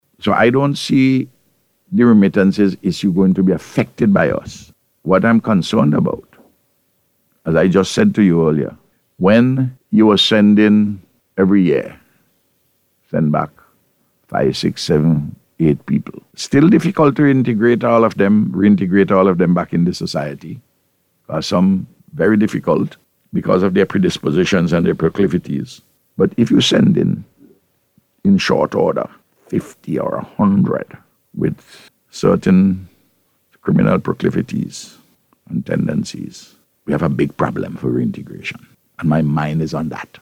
The Prime Minister who was speaking on NBC Radio at the time, said that most persons of Vincentian heritage in the United States have their legal status.